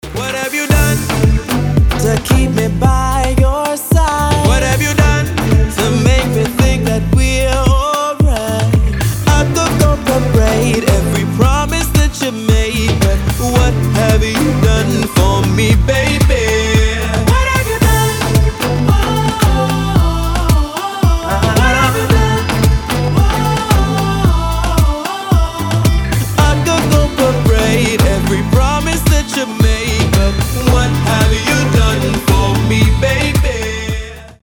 • Качество: 320, Stereo
Electronic
Neo Soul